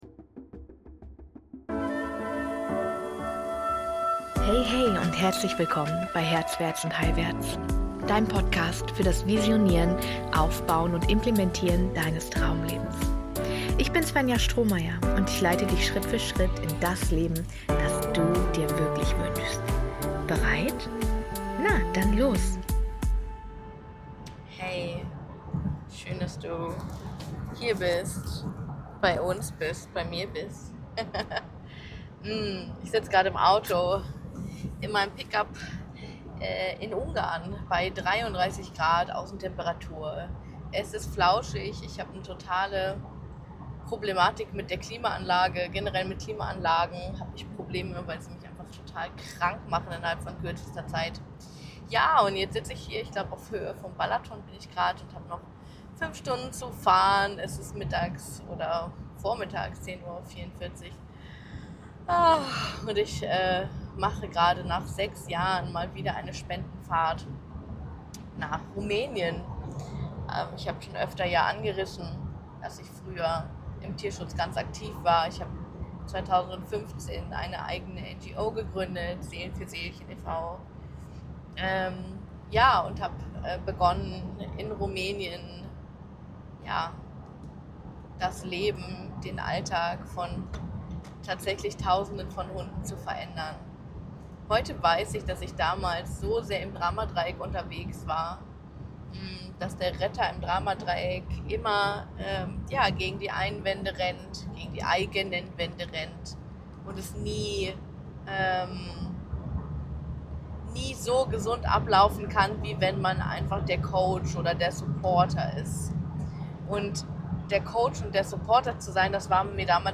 Beschreibung vor 1 Jahr Heute melde ich mich mit einer sehr persönlichen Folge von einer Spendenfahrt für den Tierschutz in Rumänien bei dir.